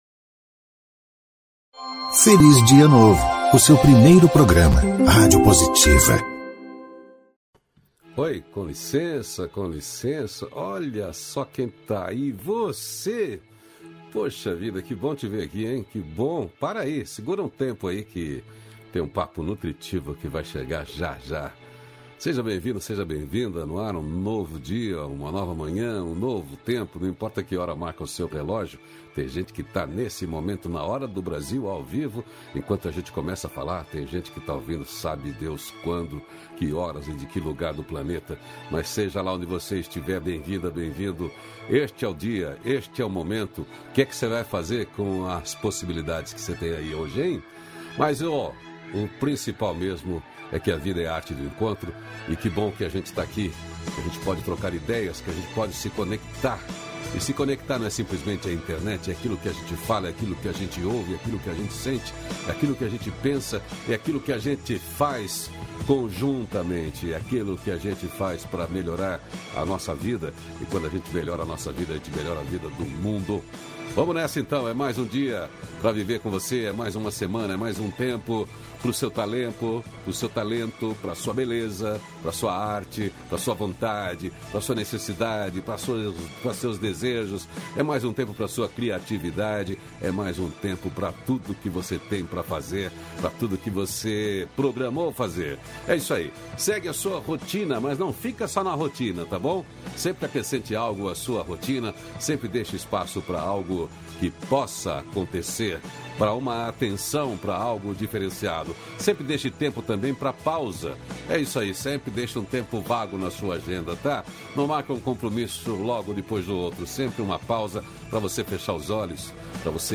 Diálogo Nutritivo